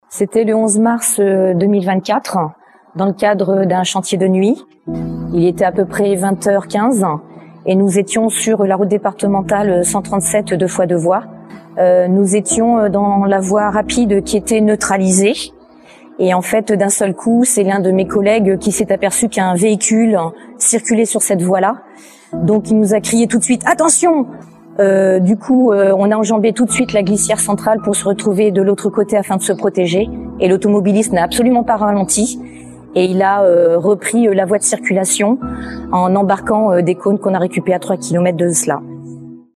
Ces témoignages feront l’objet d’une diffusion régulière sur les réseaux sociaux du Département de la Charente-Maritime.